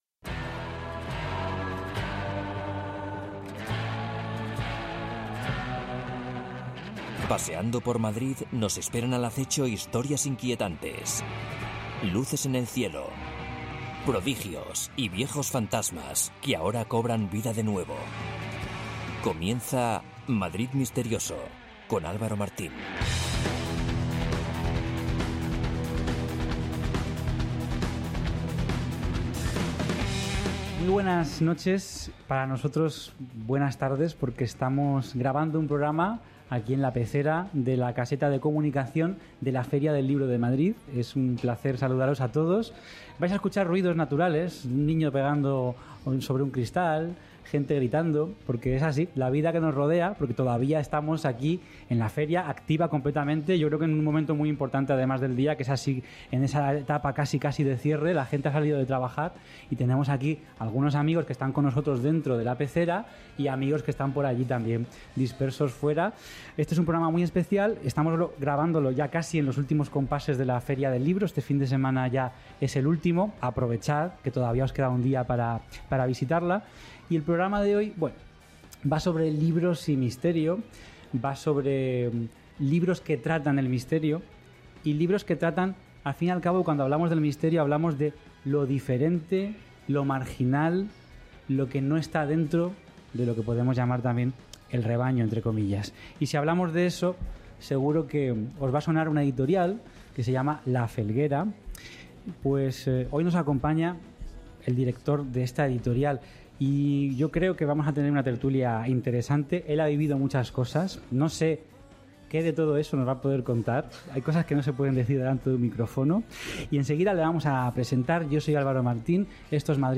Programa especial grabado desde la 82ª edición de la Feria del Libro de Madrid.